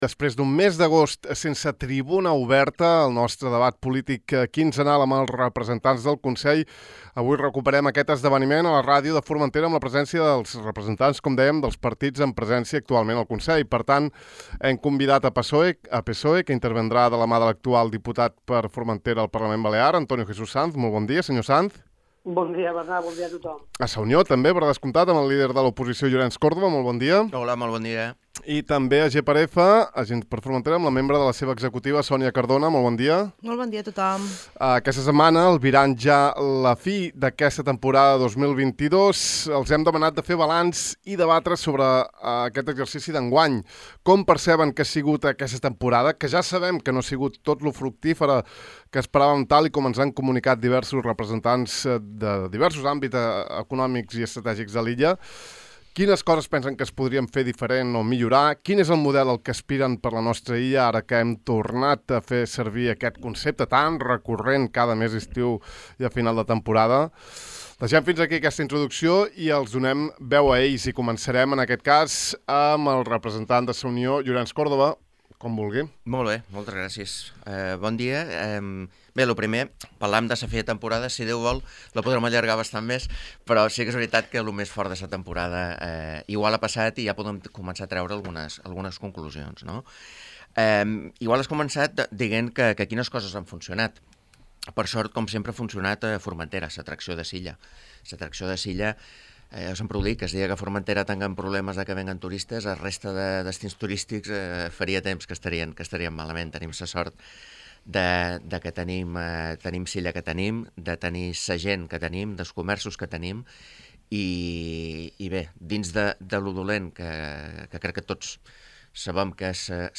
Estem a vuit de setembre, i després d’un mes d’agost sense l’espai Tribuna Oberta, el nostre debat polític quinzenal amb els representants del Consell, avui recuperem aquest esdeveniment a la ràdio de Formentera amb la presència d’un portaveu de cada partit actualment dins el Consell.